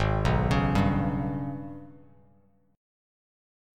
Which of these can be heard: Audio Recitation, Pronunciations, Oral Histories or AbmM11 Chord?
AbmM11 Chord